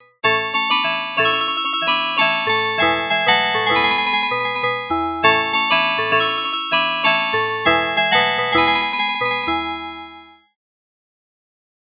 • 9 Drawbars. By sliding drawbars in or out, the user can mix various pitches and create the richly distinctive sounds of an electric organ or of a free reed aerophone instrument.
List of 64 Preset Sounds / Demo
Crystal Marimba
Harmodion-59-Crystal-Marimba.mp3